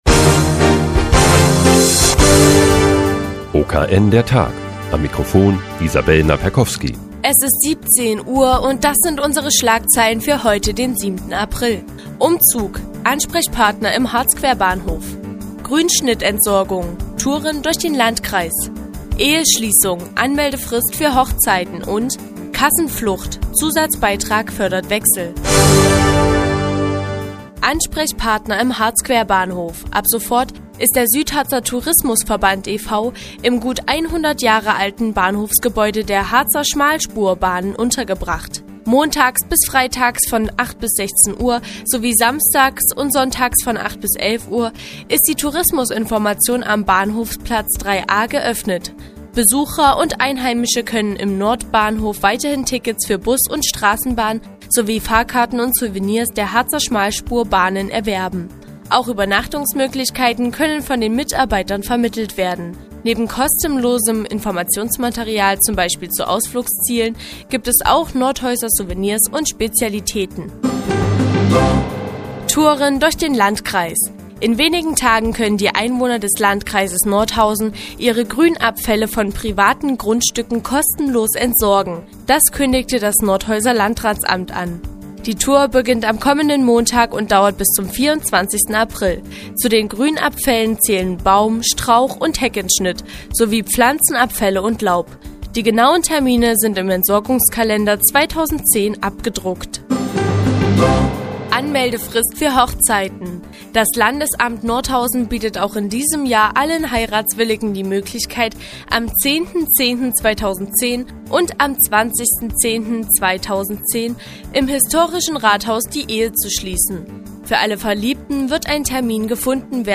Die tägliche Nachrichtensendung des OKN ist nun auch in der nnz zu hören. Heute geht es um die Grünschnittentsorgung im Landkreis Nordhausen und die Folgen des Zusatzbeitrags diverser Krankenkassen.